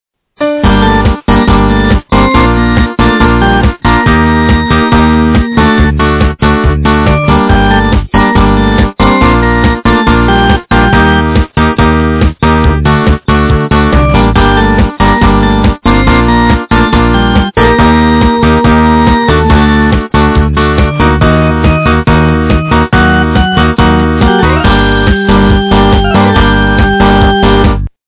русская эстрада
полифоническую мелодию